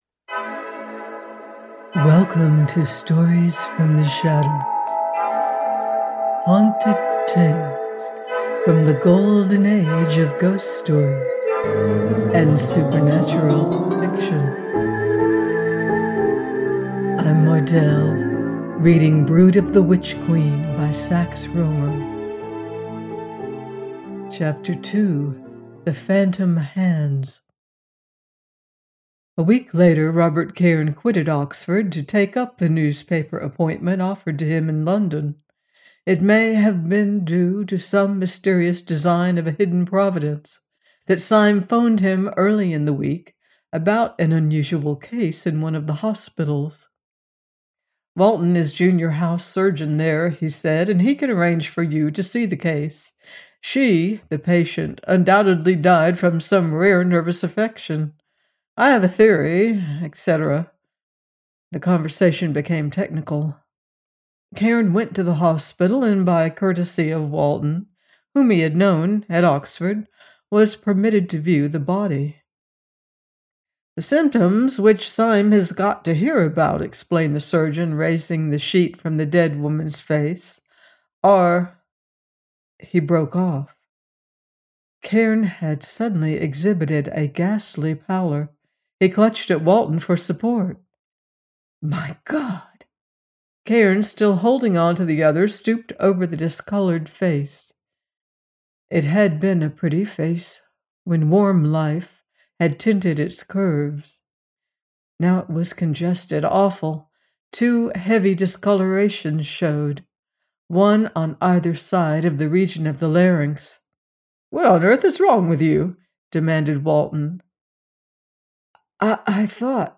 Brood of the Witch Queen – 02 : by Sax Rohmer - audiobook